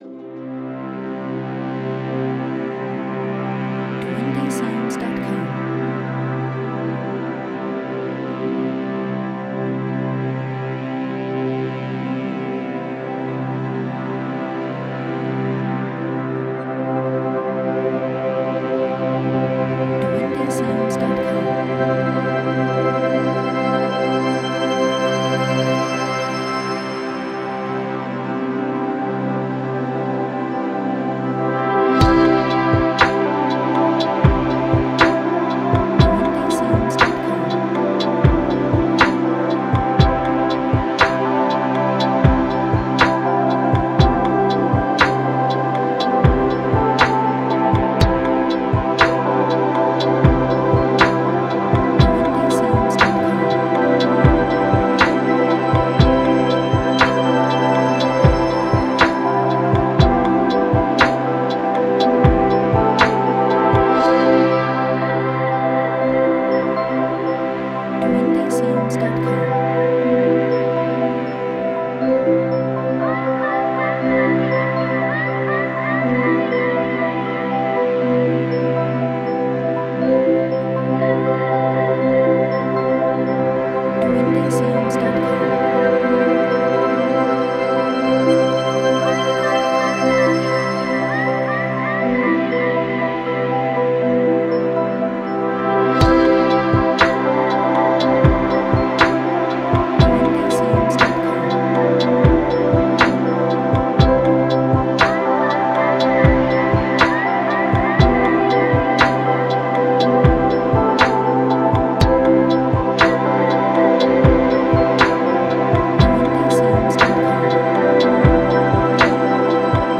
Positive;Sad;Thoughtful
Cinematic;Ambient